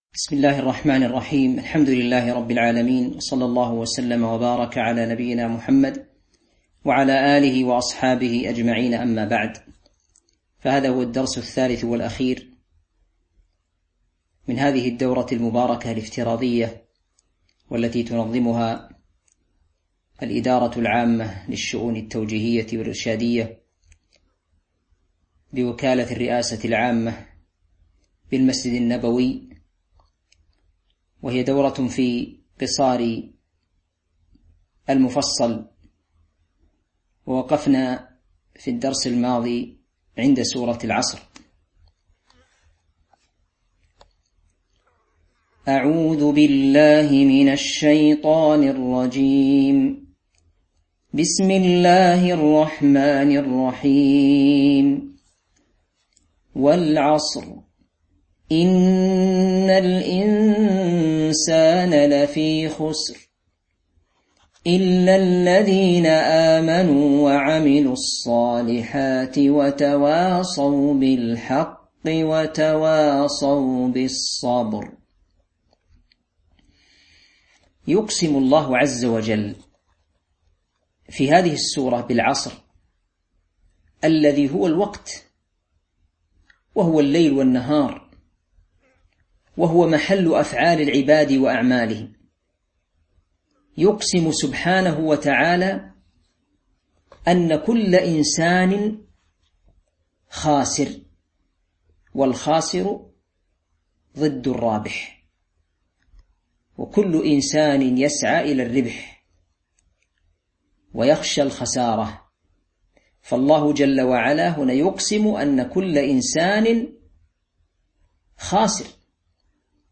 تاريخ النشر ١١ رمضان ١٤٤٢ هـ المكان: المسجد النبوي الشيخ